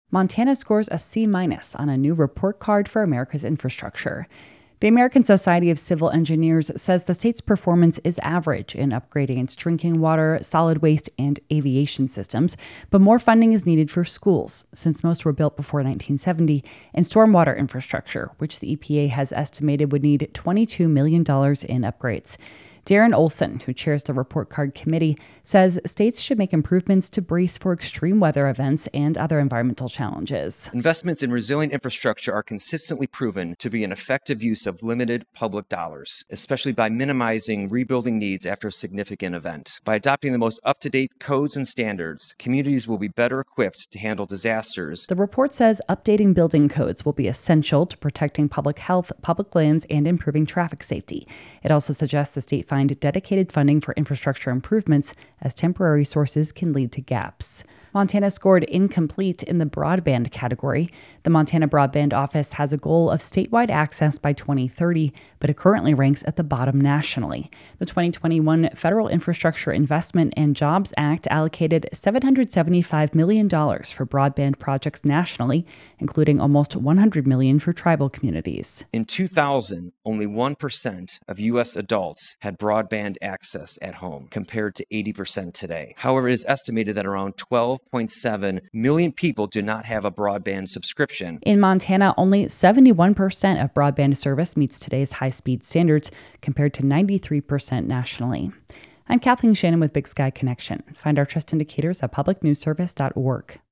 Category: City Desk